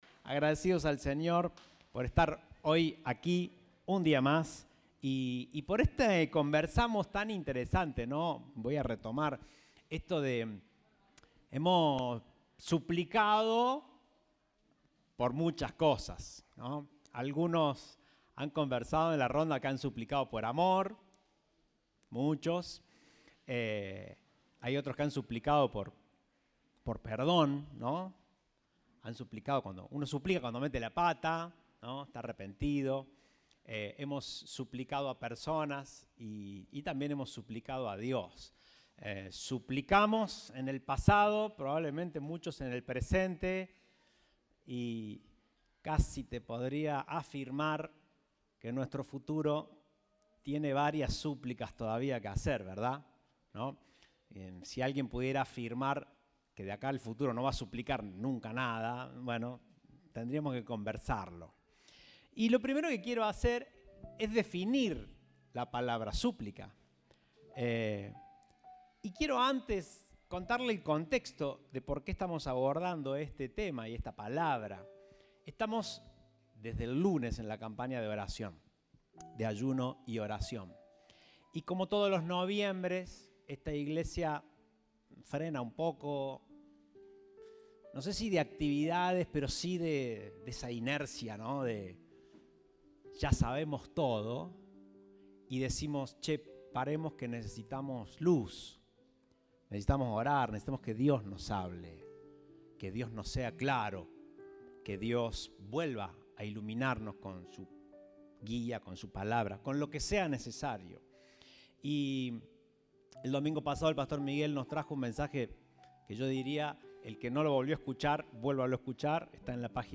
Compartimos el mensaje del Domingo 13 de Noviembre de 2022.